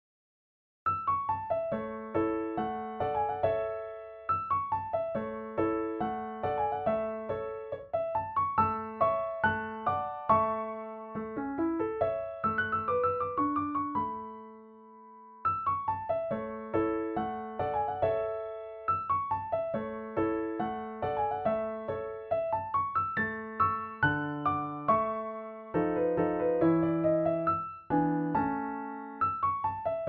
A Major
Andante